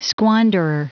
Prononciation du mot squanderer en anglais (fichier audio)
Prononciation du mot : squanderer